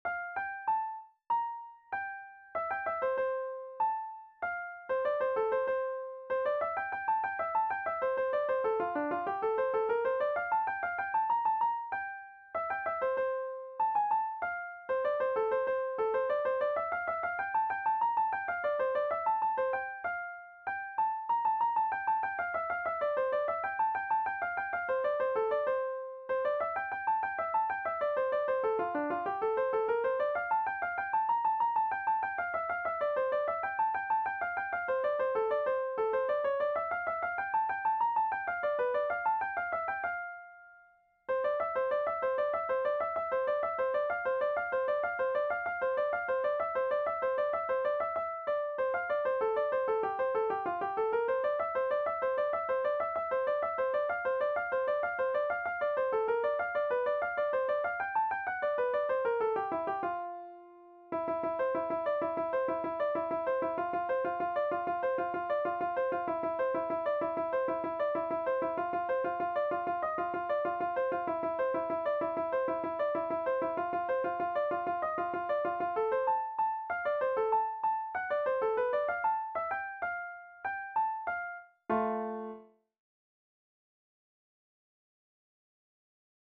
VIOLIN SOLO Violin Solo, Traditional, Fiddling Classic
DIGITAL SHEET MUSIC - VIOLIN SOLO